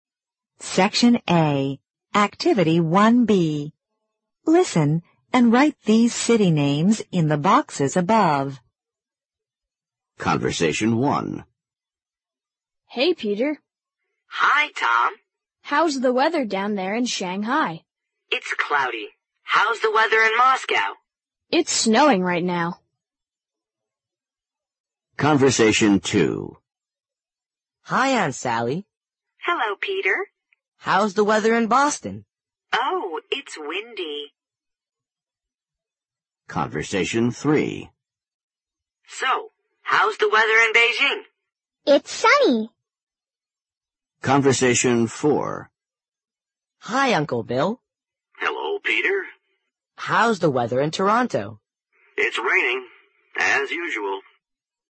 【人教版初一英语七年级下册课文朗读听力mp3】Unit 7